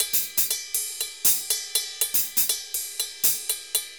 Ride_Merengue 120_2.wav